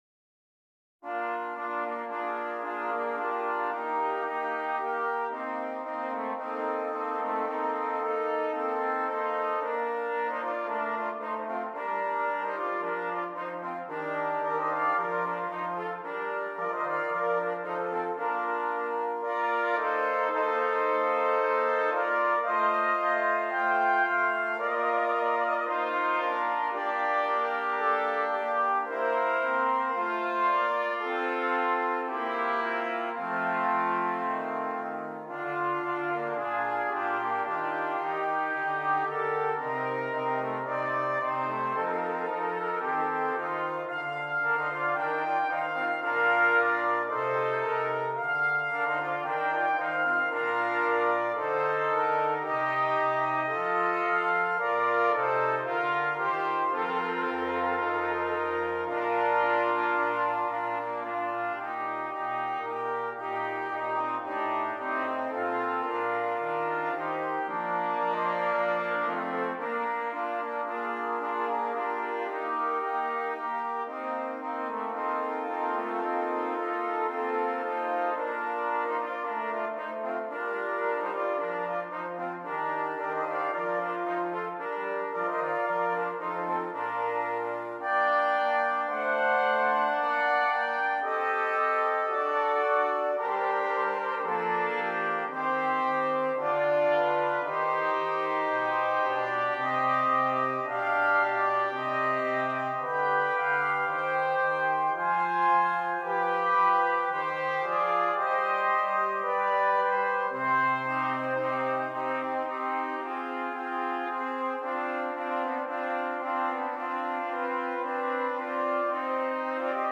5 Trumpets and Bass Clef Instrument